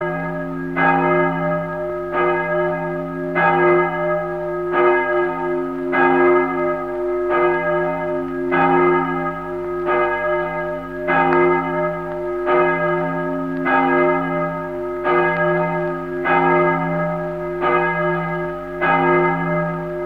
Zvony - velký zvon